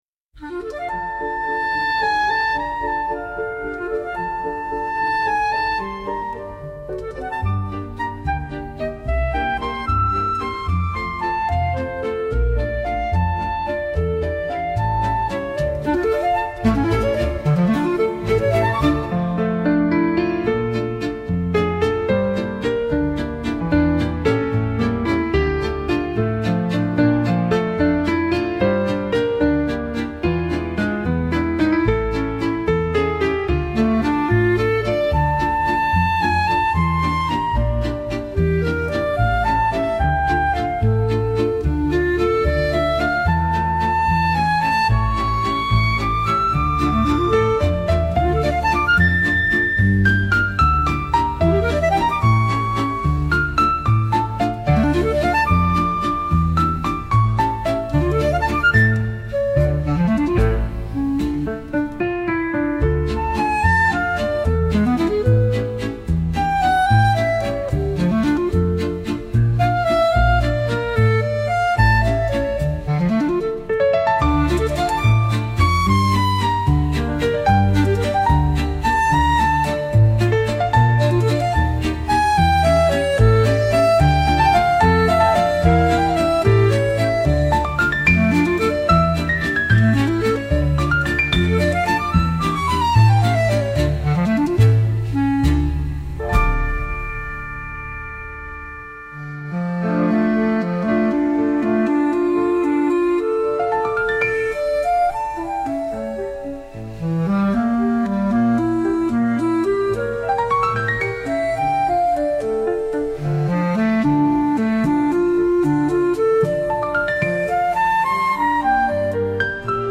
Flauto di Pan a aa strumenti musicali
MUSICA CLASSICA